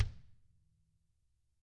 DW FELT PD-R.wav